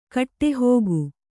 ♪ kaṭṭehōgu